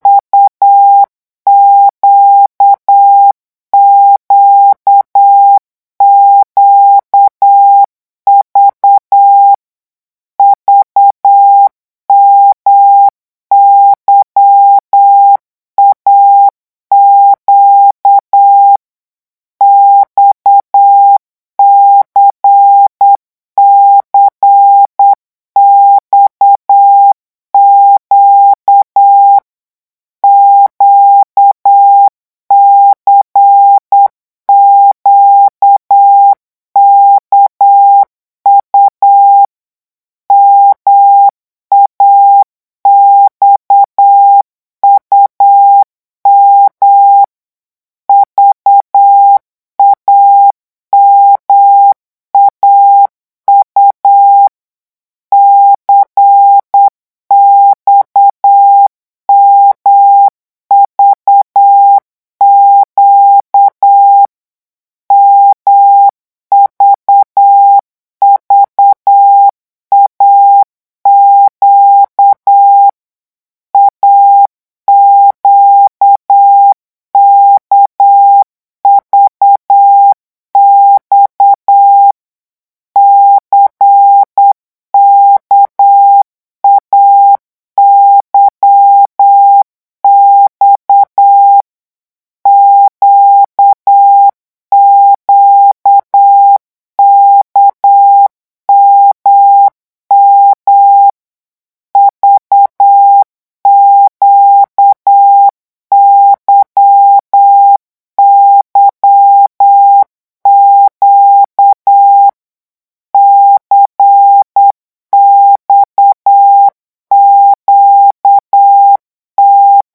LE CODE MORSE - Révision 2
8 mots minutes
revision2-vitesse_8_mots.mp3